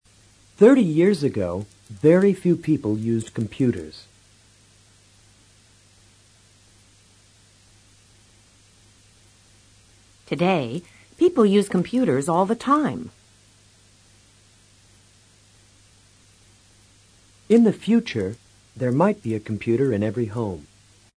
Curso de Pronunciación en Inglés
PRACTICA DE ENTONACION: Entonación ascendente y descendente.